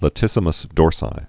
(lə-tĭsə-məs dôrsī)